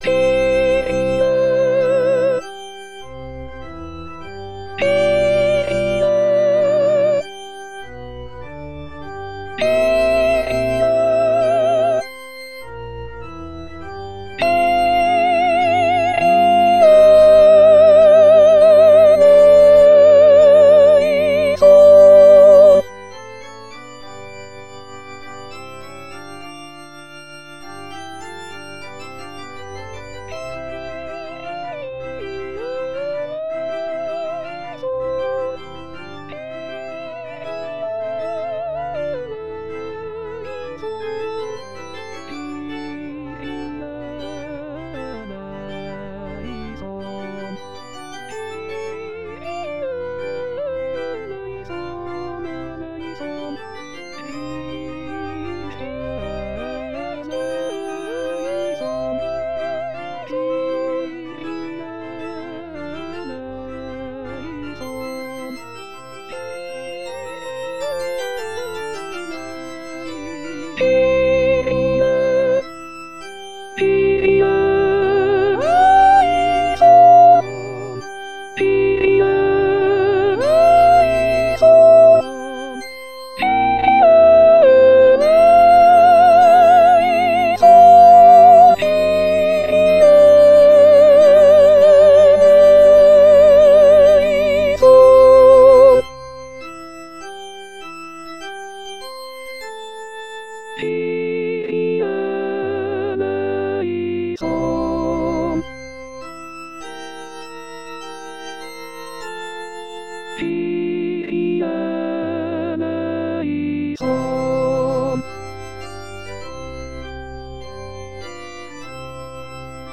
Les aides traditionnelles avec voix de synthèse
Kyrie – Soprano
Kyrie-Soprano.mp3